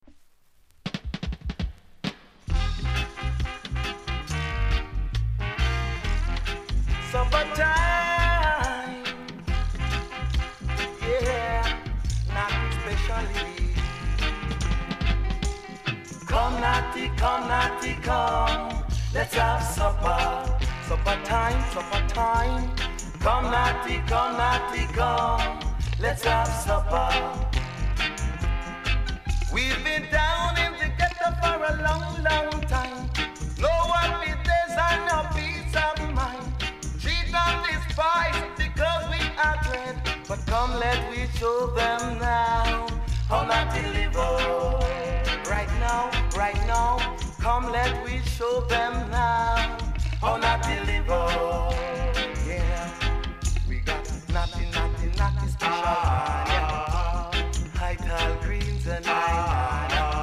コメント 渋いROOTS ROCK REGGAE!!